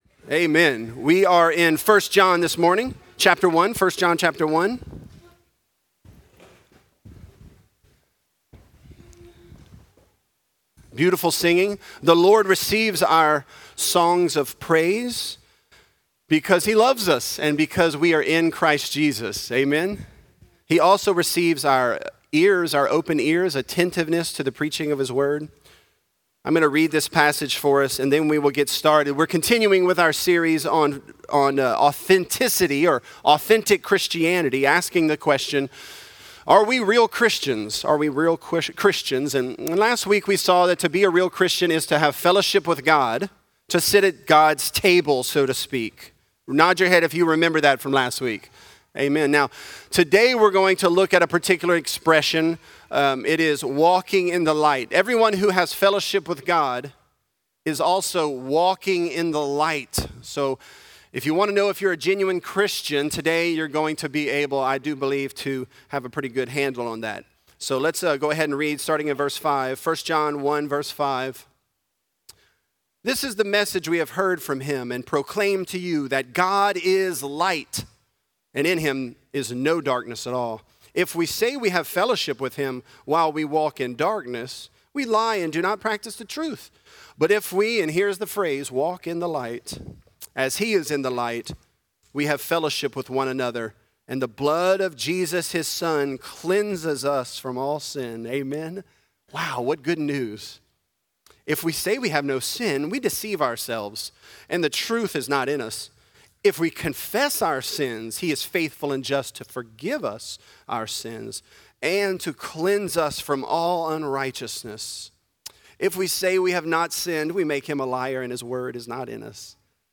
Authentic: The Blood Of Christ | Lafayette - Sermon (1 John 1)